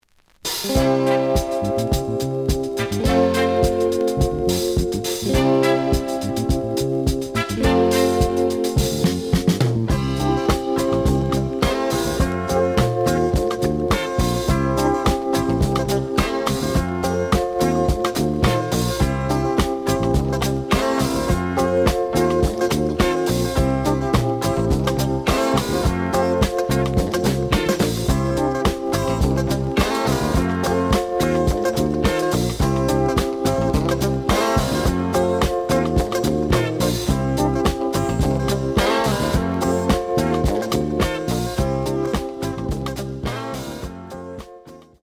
(Instrumental)
The audio sample is recorded from the actual item.
●Genre: Soul, 70's Soul